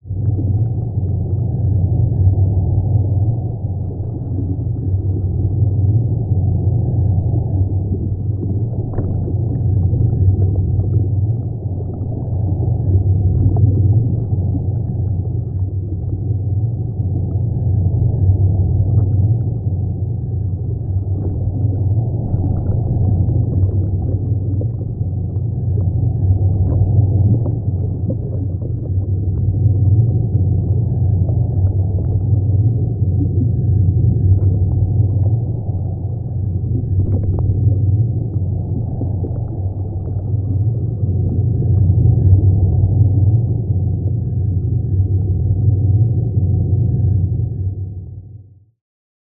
Тут вы можете прослушать онлайн и скачать бесплатно аудио запись из категории «Вода, шум воды».